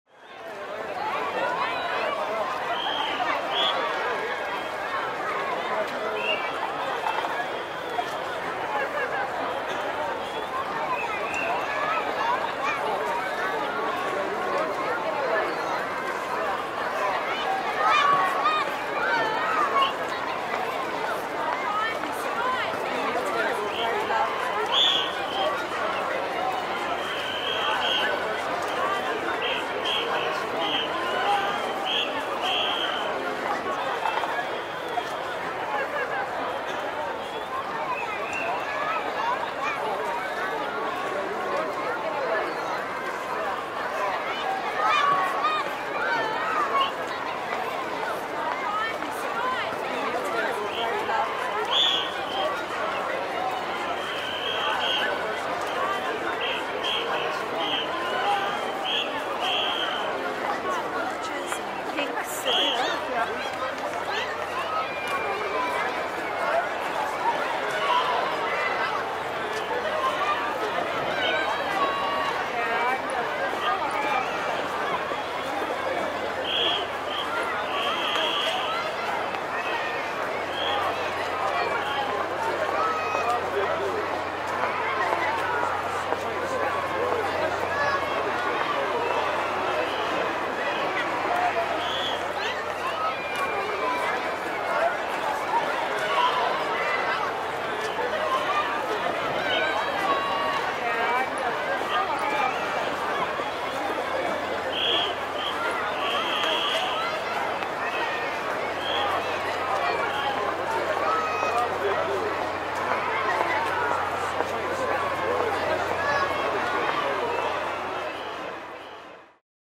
Звонкий смех детей во дворе